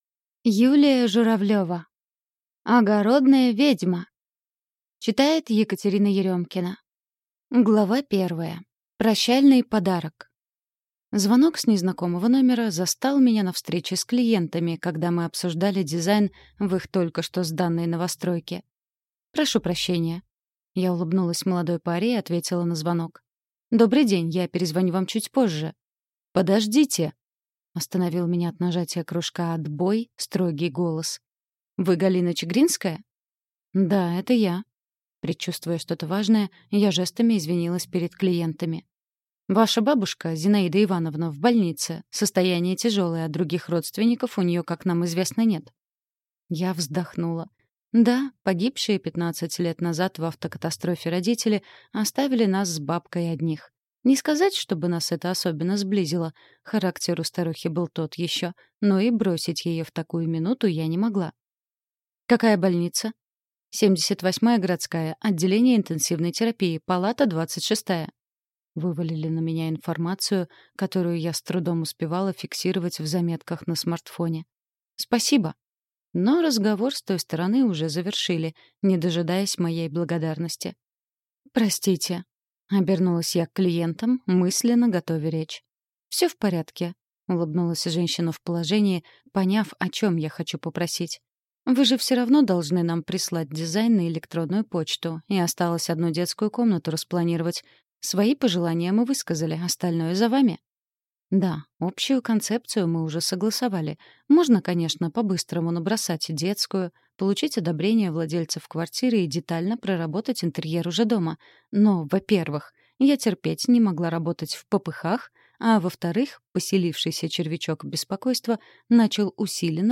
Аудиокнига Огородная ведьма | Библиотека аудиокниг
Прослушать и бесплатно скачать фрагмент аудиокниги